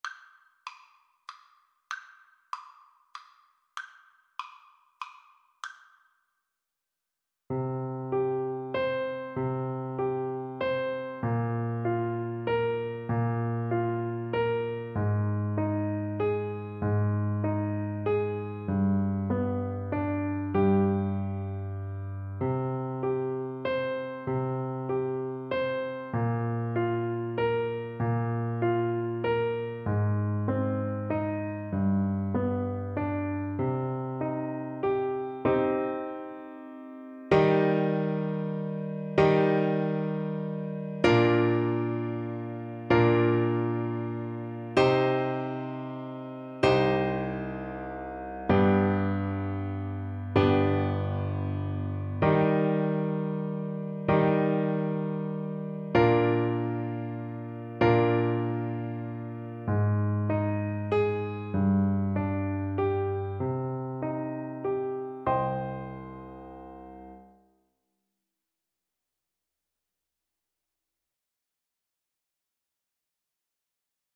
Baritone Saxophone
"Greensleeves" is a traditional English folk song and tune, over a ground either of the form called a romanesca or of its slight variant, the passamezzo antico.
G3-Bb4
Slow one in a bar feel . = c.46
greensleeves_BSAX_kar3.mp3